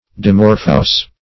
Dimorphous \Di*mor"phous\, a. [Cf. F. dimorphe.]